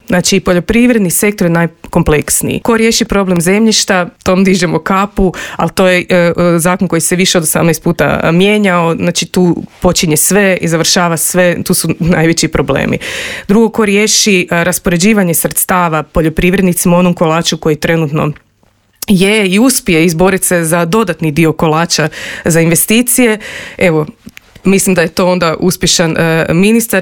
O tome kako spasiti poljoprivrednike, razgovarali smo u Intervjuu Media servisa s voditeljicom Odjela za poljoprivrednu politiku